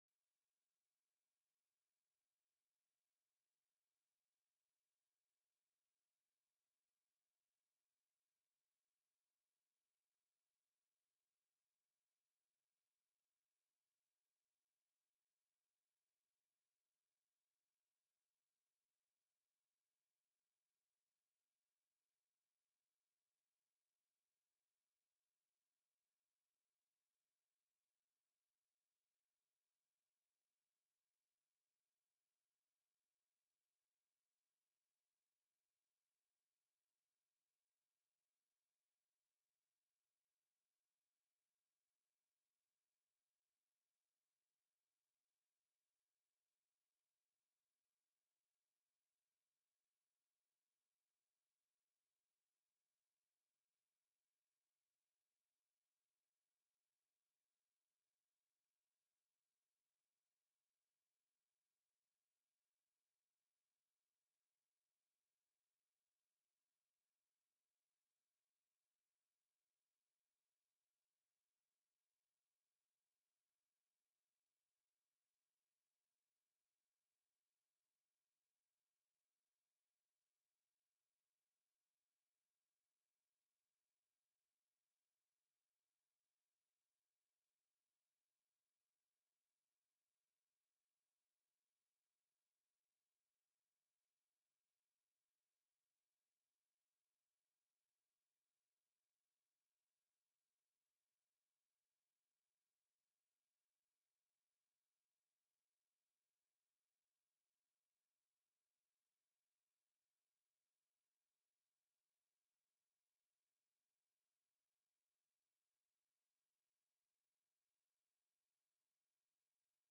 The audio recordings are captured by our records offices as the official record of the meeting and will have more accurate timestamps.
HB 263 APPROP: OPERATING BUDGET;AMEND;SUPP TELECONFERENCED